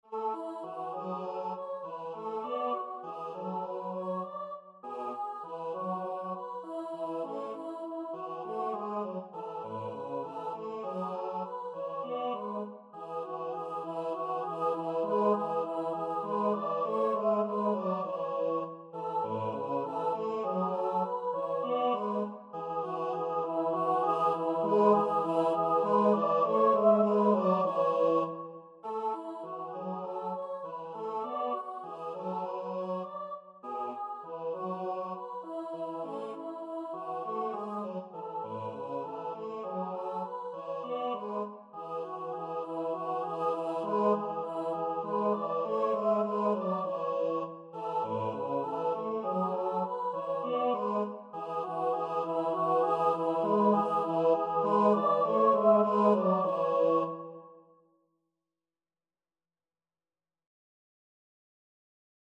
Partitura e file MIDI